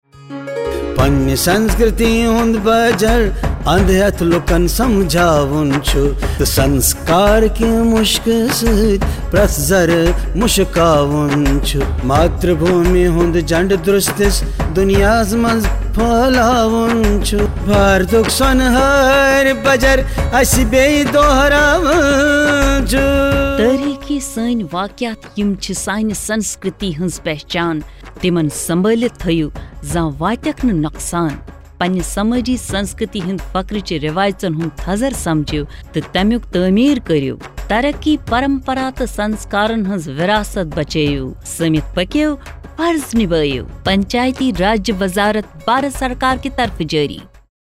85 Fundamental Duty 6th Fundamental Duty Preserve composite culture Radio Jingle Kashmiri